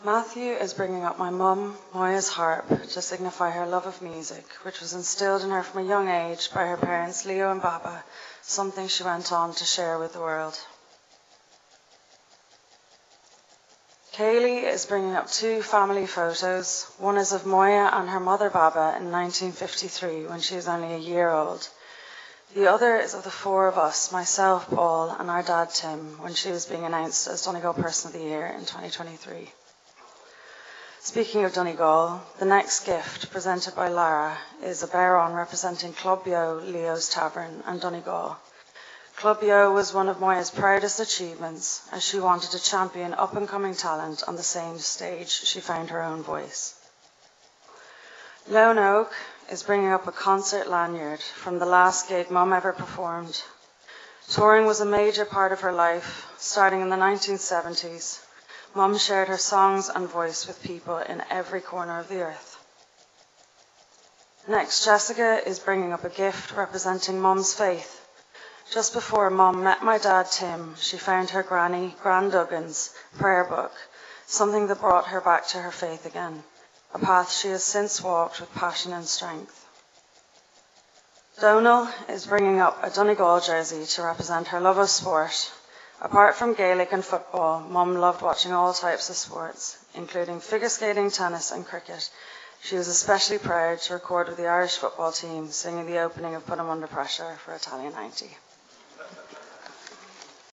The funeral mass for Moya Brenna is underway at St Patrick’s Church, Meenaweal, with a large crowd gathered.
As the funeral mass started, a number of gifts were brought to the altar.